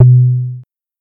دانلود افکت صوتی صدای عصر طلایی